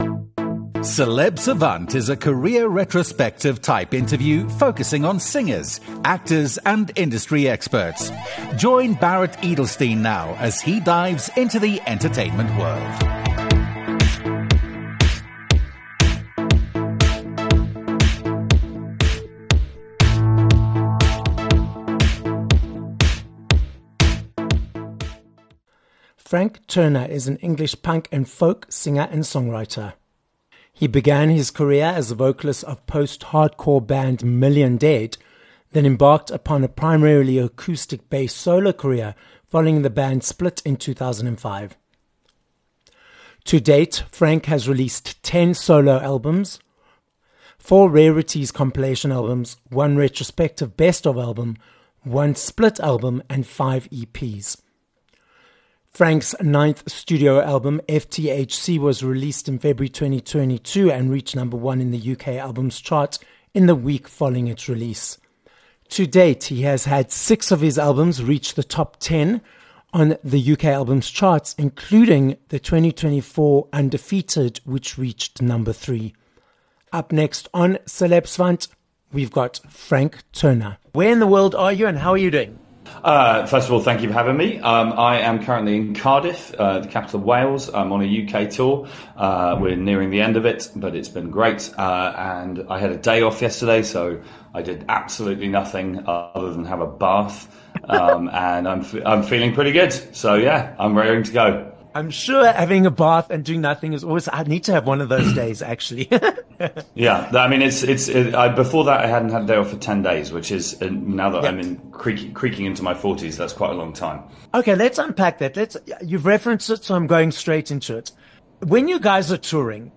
29 Aug Interview with Frank Turner
With six albums hitting the top 10 on the UK album charts - including a No. 1 album - we are joined on this episode of Celeb Savant by British singer and songwriter, Frank Turner. Frank tells us about being in the industry since the age of 16, the behind-the-scenes of what happens on tour and about his successful journey in the industry.